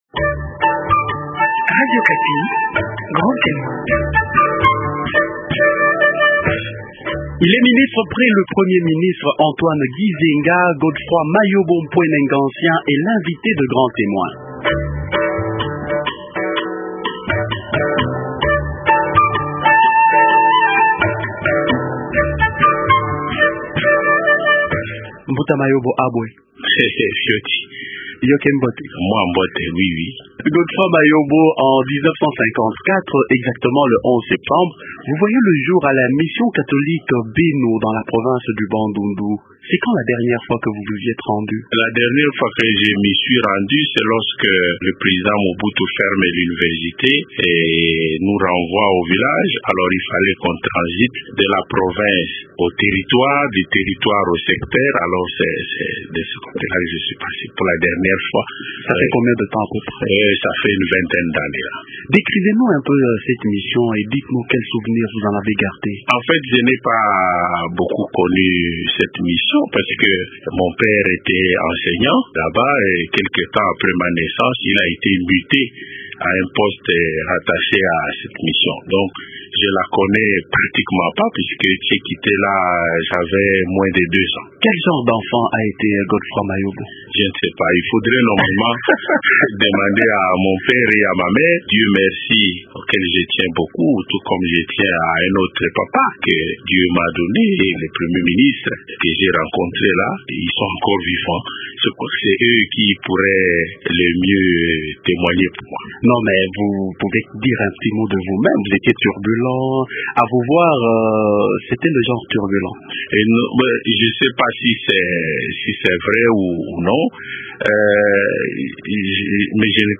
Télécharger Mayobo Mpwene Ngantien Godefroid Mayobo Mpwene Ngantien est ministre près le premier ministre Antoine Gizenga.
entretien